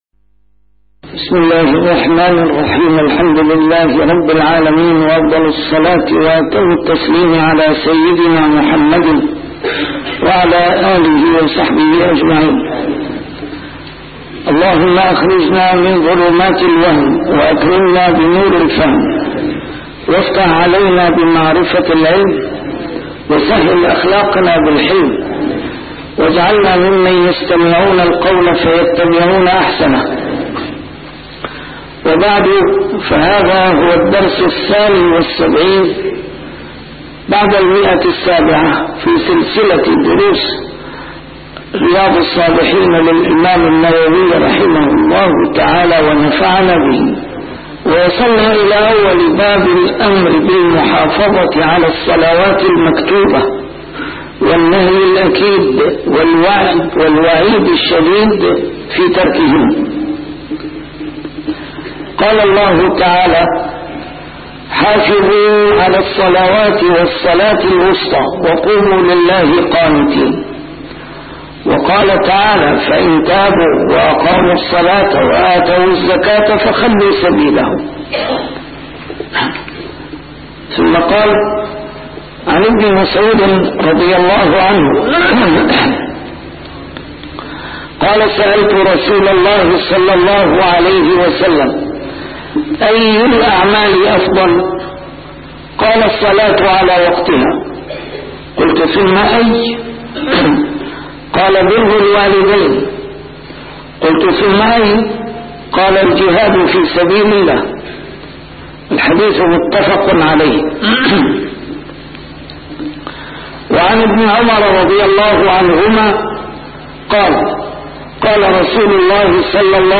شرح كتاب رياض الصالحين - A MARTYR SCHOLAR: IMAM MUHAMMAD SAEED RAMADAN AL-BOUTI - الدروس العلمية - علوم الحديث الشريف - 772- شرح رياض الصالحين: المحافظة على الصلوات المكتوبات